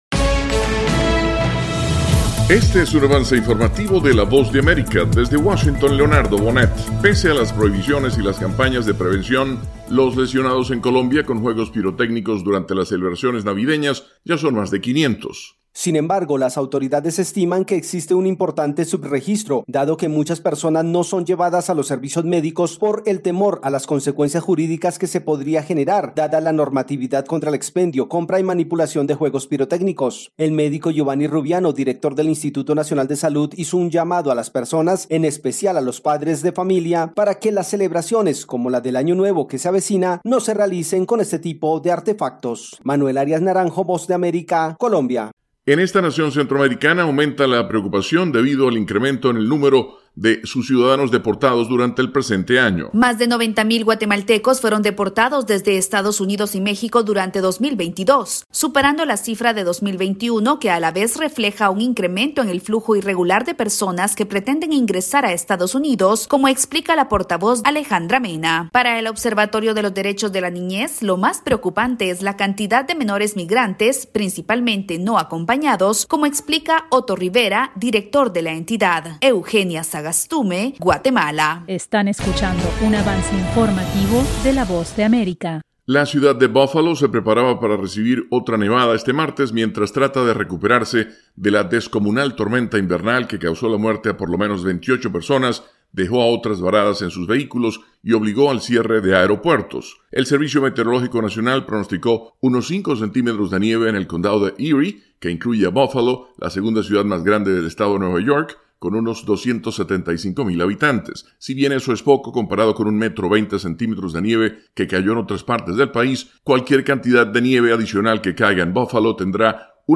Avance Informativo 1:00 PM